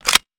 weapon_foley_drop_17.wav